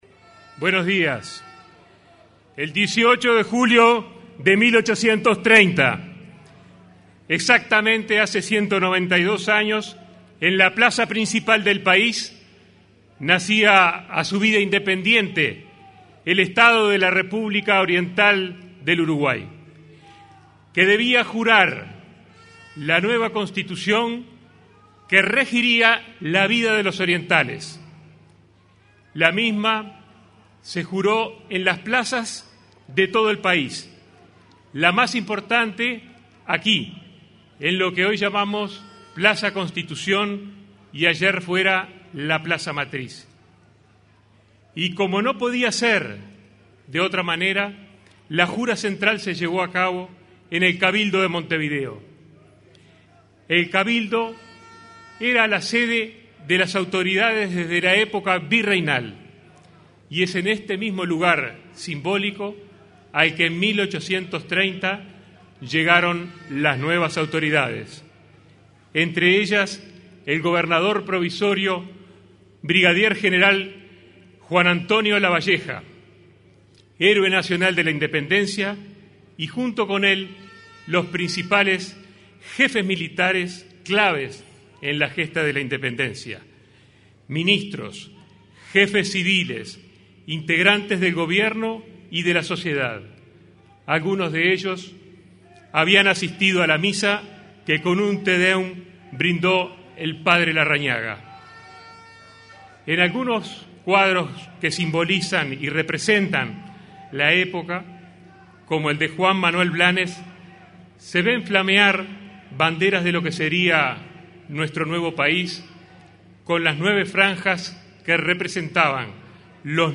Celebración del 192.° aniversario de la Jura de la Constitución 18/07/2022 Compartir Facebook X Copiar enlace WhatsApp LinkedIn En representación del Poder Ejecutivo, el ministro de Salud Pública, Daniel Salinas, realizó la oratoria en la celebración del 192.° aniversario de la Jura de la Constitución.